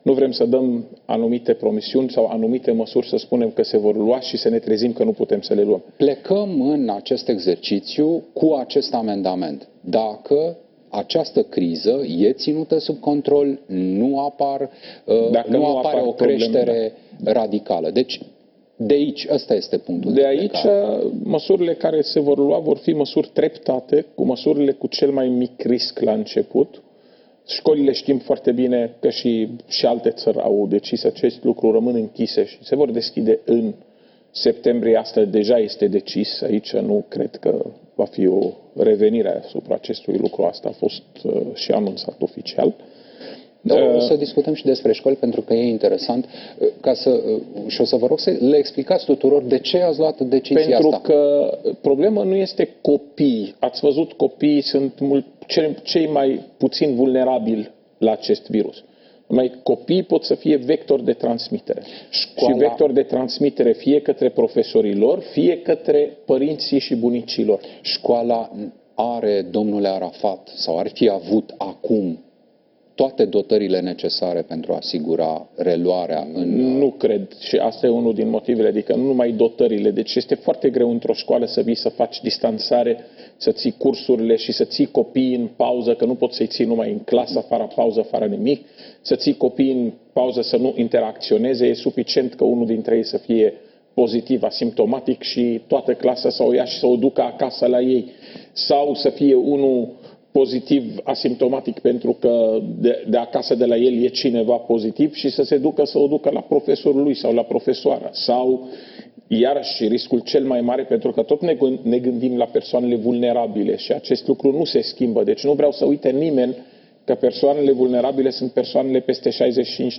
Raed Arafat a precizat, la Digi 24, că măsurile sunt în analiză, dar în toate mijloacele de transport în comun masca va fi obligatorie, iar șoferii nu ar trebui să lase călătorii să urce fără mască.